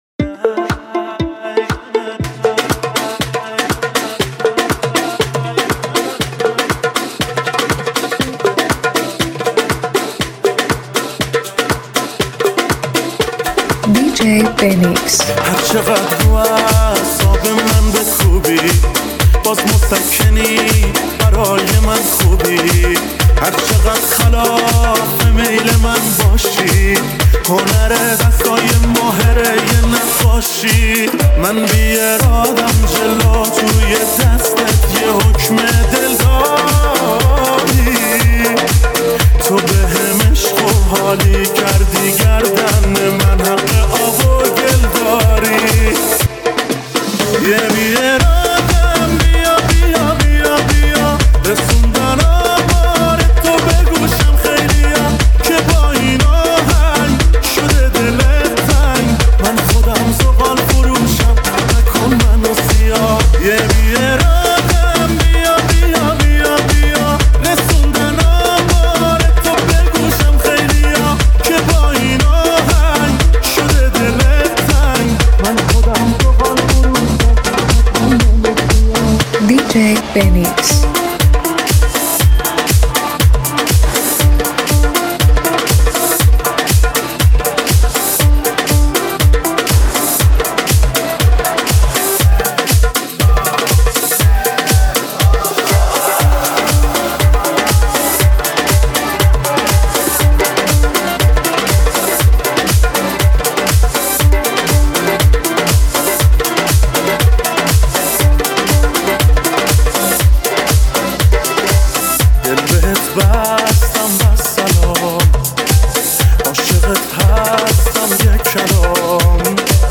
ریمیکس هیجان‌انگیز و پرانرژی